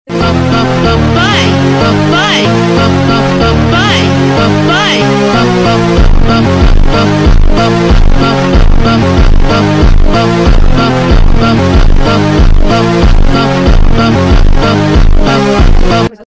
Los audios subliminales son sonidos especialmente mezclados con afirmaciones positivas para que sean percibidas por el subconsciente directamente. El audio subliminal puede ser una canción o un sonido relajante mezclado con afirmaciones positivas que se desea que lleguen a la mente subconsciente.